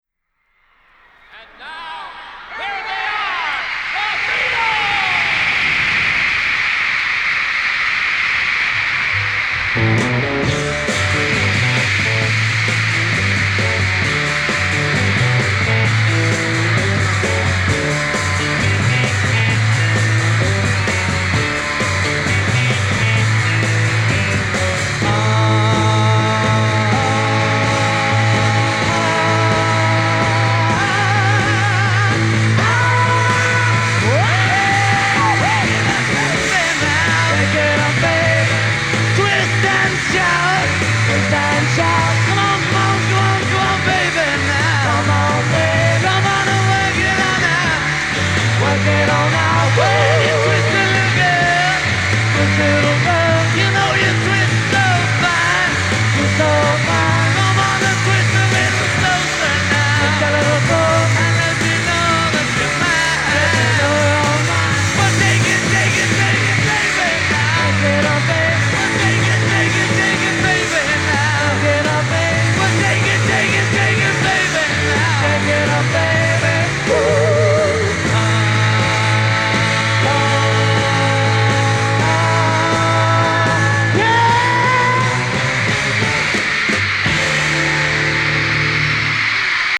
multiple concerts
virtually unlistenable
crowd noise